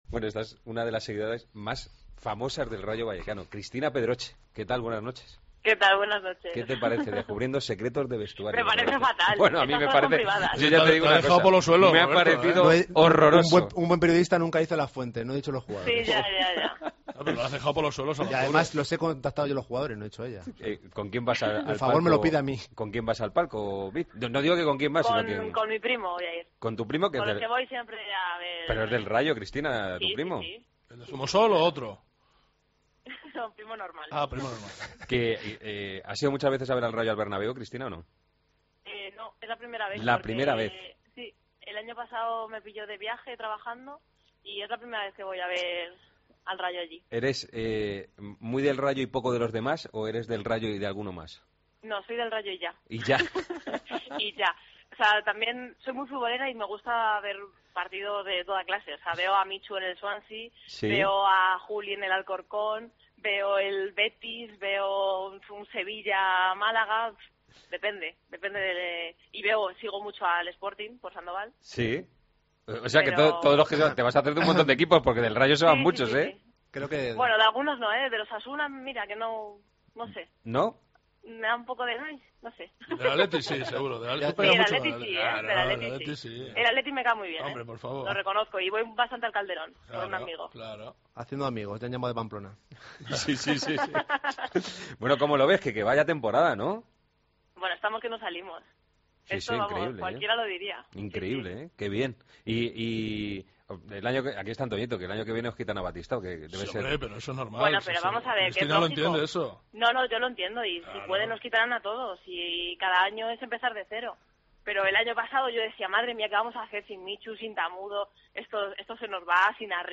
AUDIO: La presentadora aficionada del Rayo Vallecano pasó por los micrófonos de Tiempo de Juego antes del partido que enfrenta al Real Madrid...